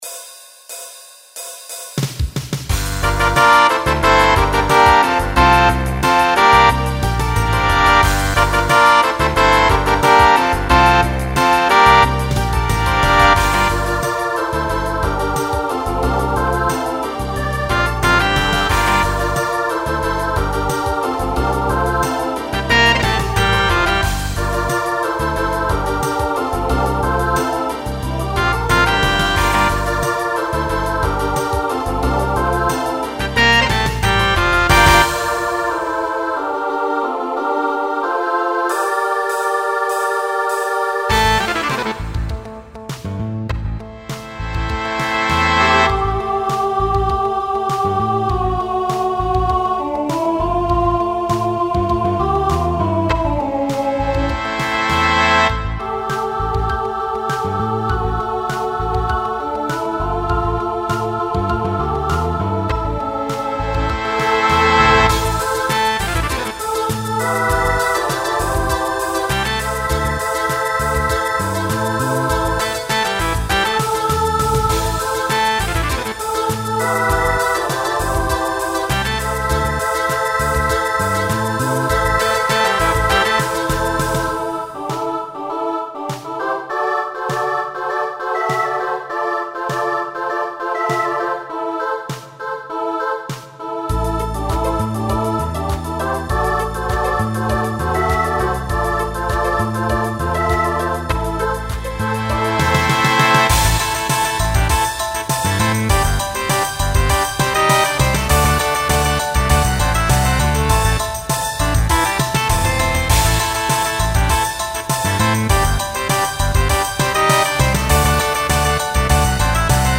Genre Pop/Dance
Transition Voicing SSA